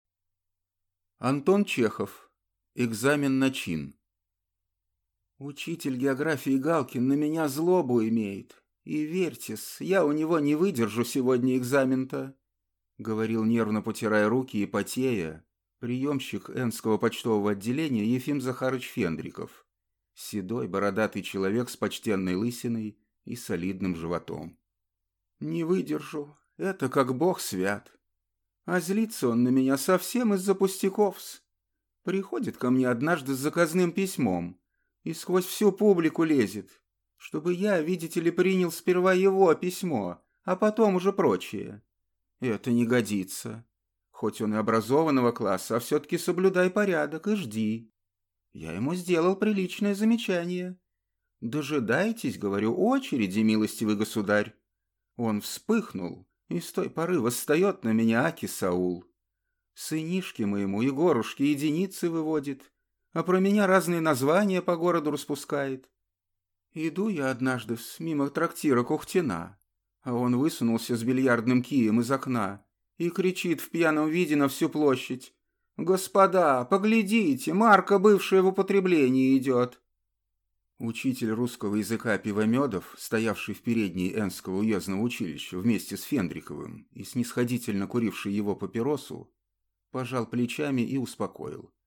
Аудиокнига Экзамен на чин | Библиотека аудиокниг